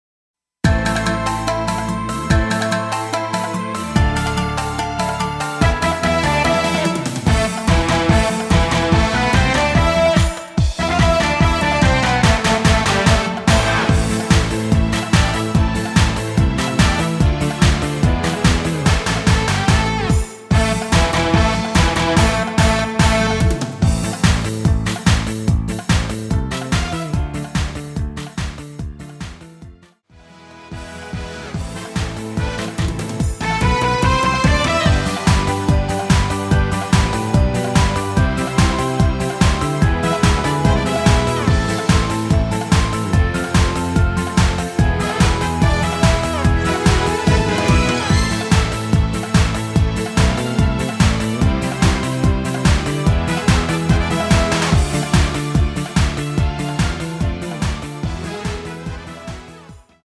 Am
◈ 곡명 옆 (-1)은 반음 내림, (+1)은 반음 올림 입니다.
앞부분30초, 뒷부분30초씩 편집해서 올려 드리고 있습니다.